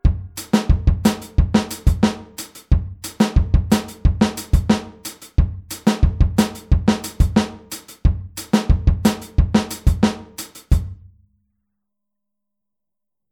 Hier spielen wir den Offbeat mit der rechten Hand wieder auf dem HiHat.